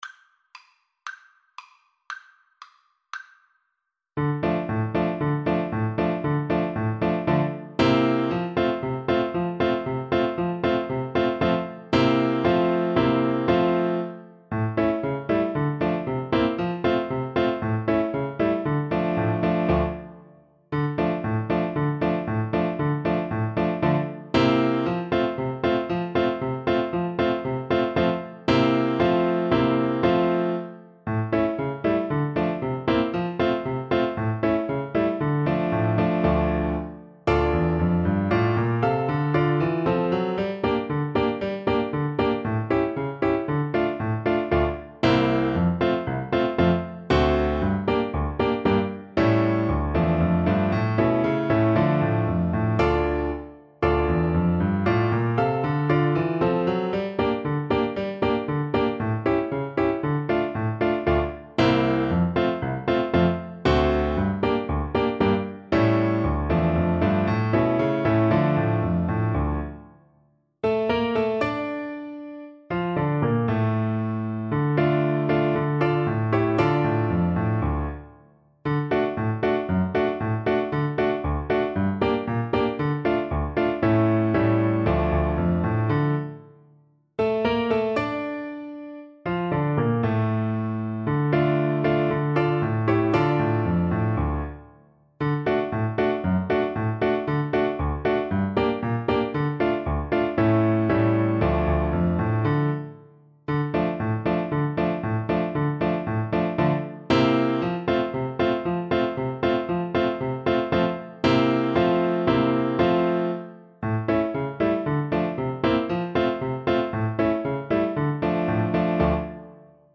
2/4 (View more 2/4 Music)
Allegro =c.116 (View more music marked Allegro)